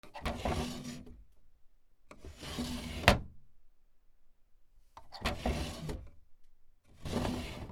『シュー』